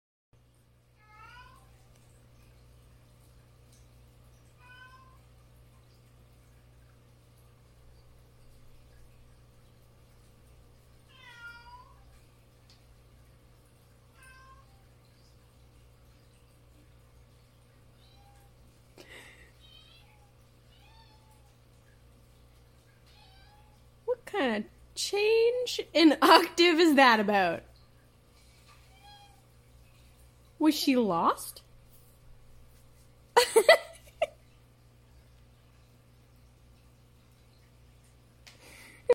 listen to our deaf cats sound effects free download
listen to our deaf cats meow change when she notices her favorite human